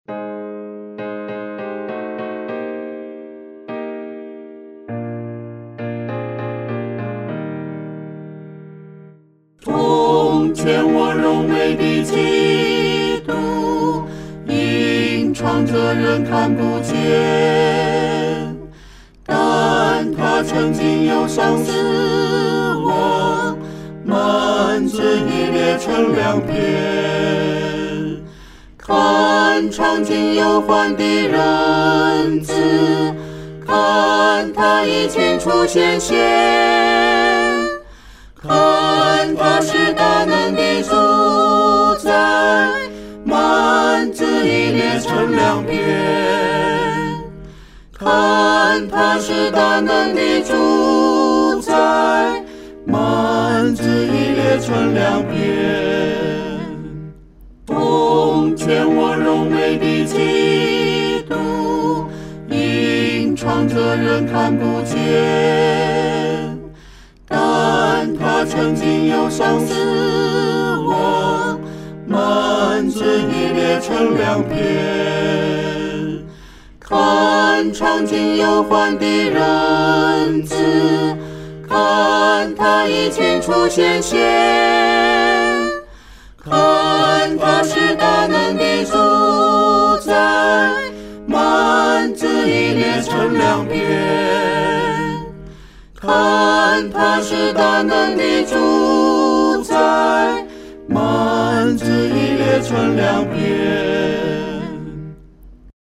合唱
四声